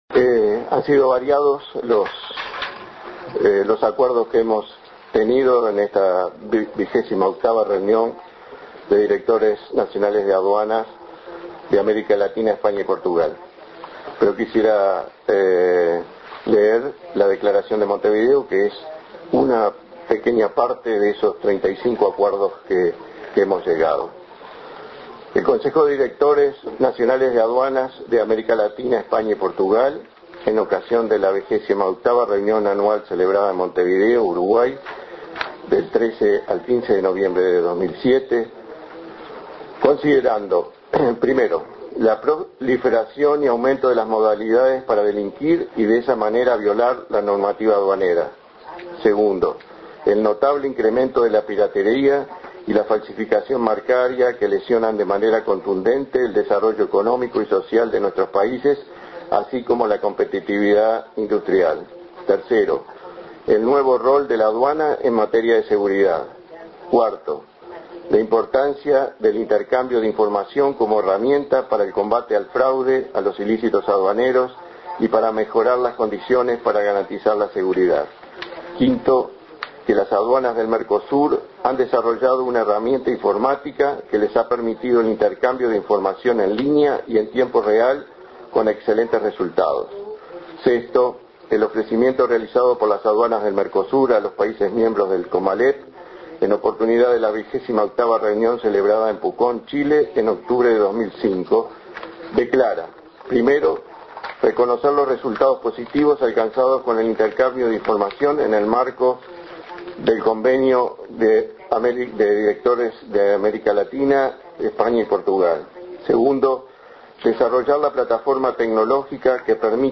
En el acto de clausura, Nin, dijo que el evento se desarroll� en un momento muy especial para Uruguay, ya que el pa�s se encuentra inserto en un profundo y ascendente proceso de cambios.